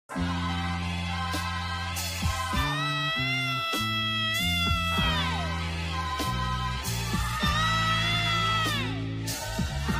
Download Dog sound effects in mp3 format for free without login or sign-up and find similar sounds at Quick Sounds library.